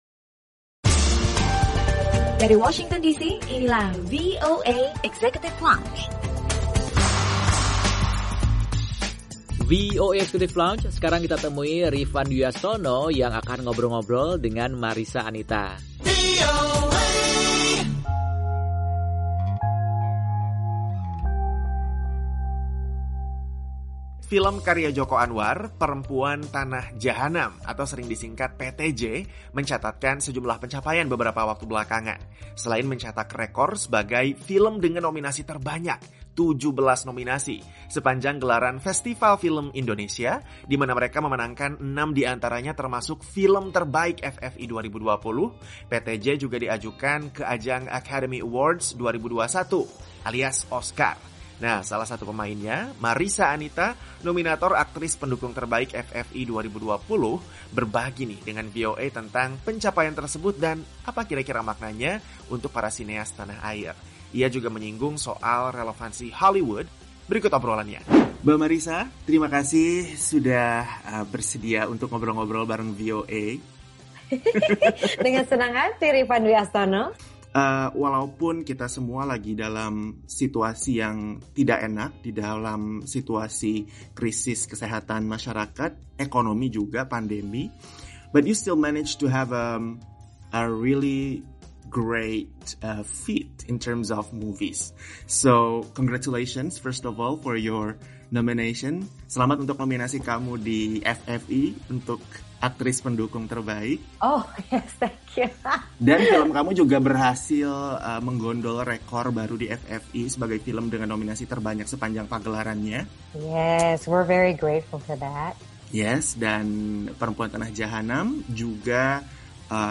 Obrolan bersama Marissa Anita, nominator Aktris Pendukung Terbaik FFI 2020, yang juga bermain di Film karya Joko Anwar, Perempuan Tanah Jahanam, film yang meraih banyak prestasi di ajang FFI 2020 dan diajukan ke ajang Academy Awards 2021 alias The Oscars.